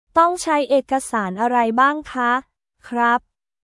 トン・チャイ・エーカサーン・アライ・バーン・カ／クラップ